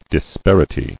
[dis·par·i·ty]